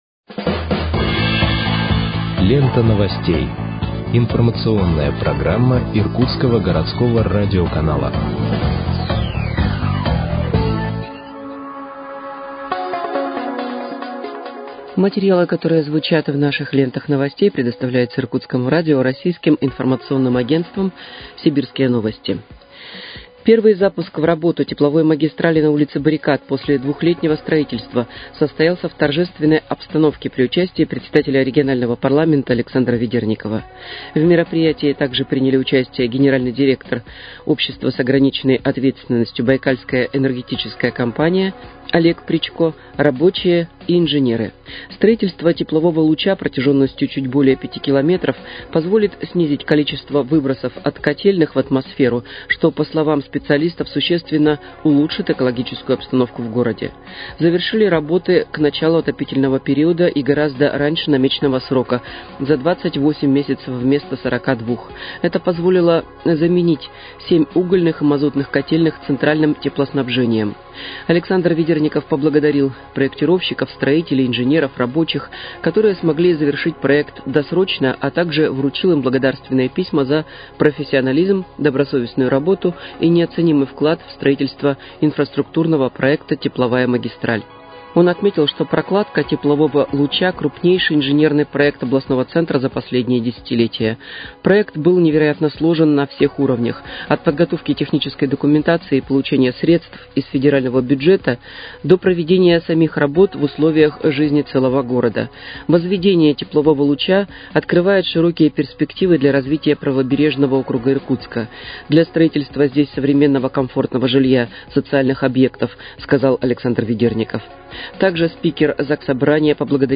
Выпуск новостей в подкастах газеты «Иркутск» от 14.11.2025 № 2